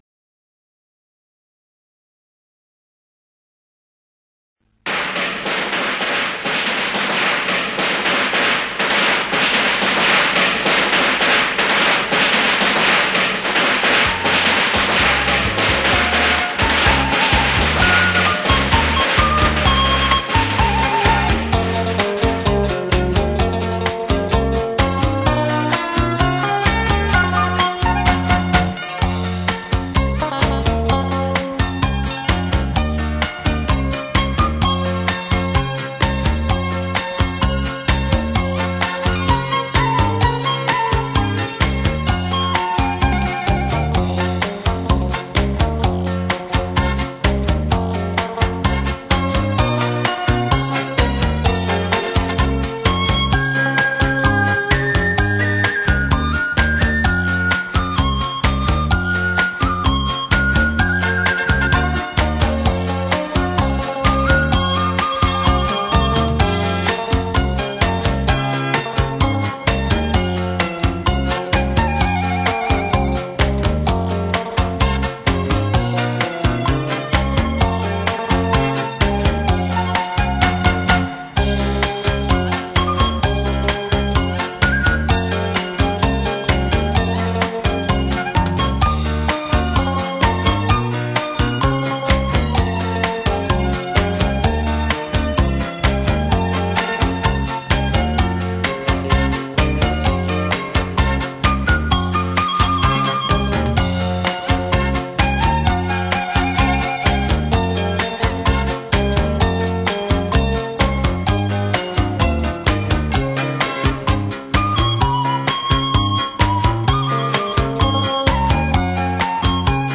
[23/1/2009]“祝大家春节快乐”粤语小调《祝君快乐》 激动社区，陪你一起慢慢变老！